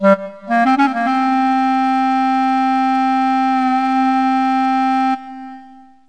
/ cdmania.iso / sounds / music / saxsolo.wav ( .mp3 ) < prev next > Waveform Audio File Format | 1996-04-15 | 68KB | 1 channel | 22,050 sample rate | 6 seconds
saxsolo.mp3